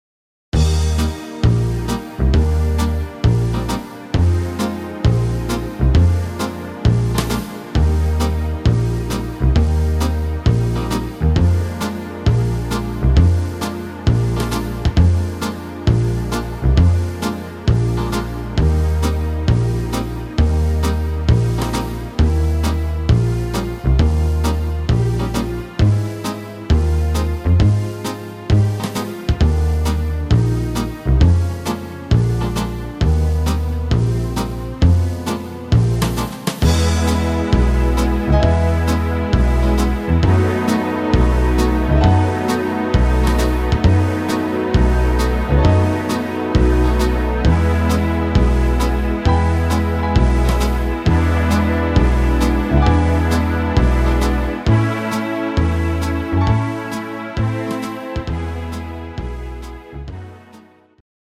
instr. Orchester